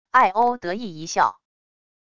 艾欧得意一笑wav音频